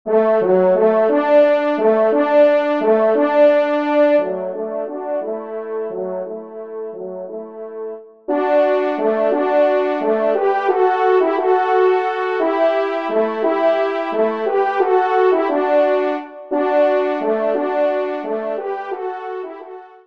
Genre :  Divertissement pour Trompes ou Cors
2e Trompe